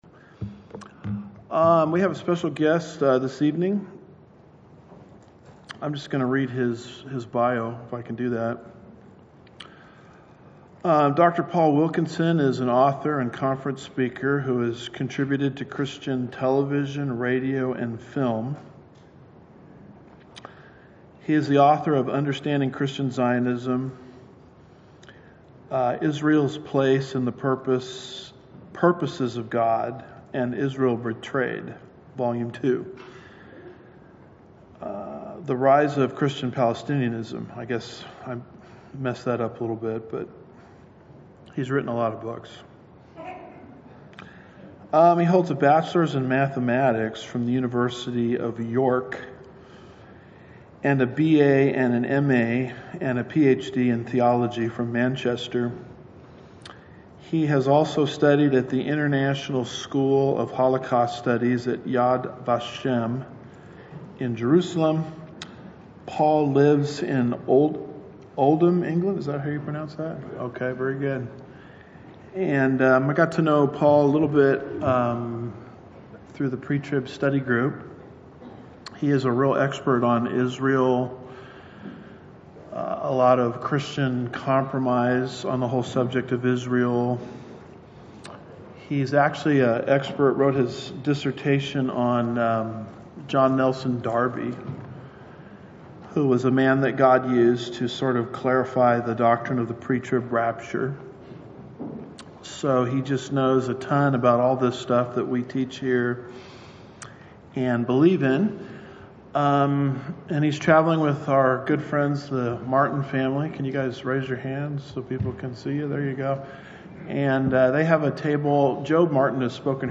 Home / Sermons / What State Is Israel In?
2025 • Guest Speaker Listen Now Download Audio Slides Previous Sermon Gideon